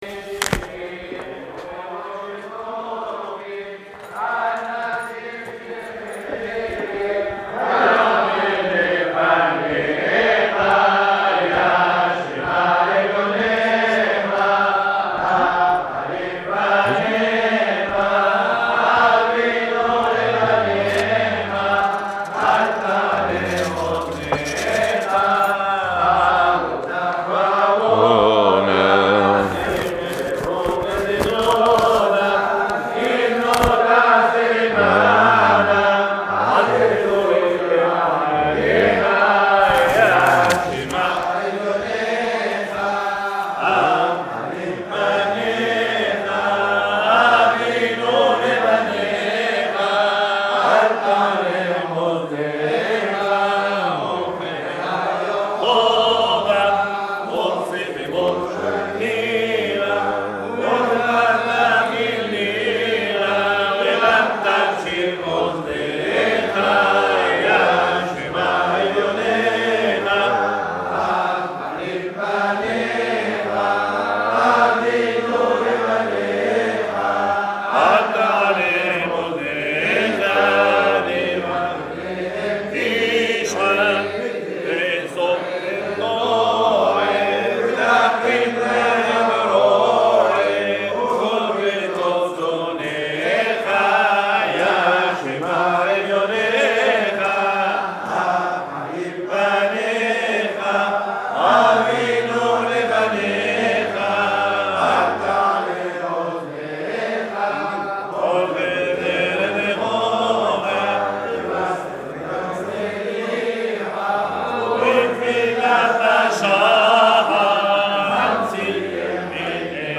50. Selikhotes - Selih’otes 5773 à la synagogue "Rebbi Hai Taieb Lo Met" à Paris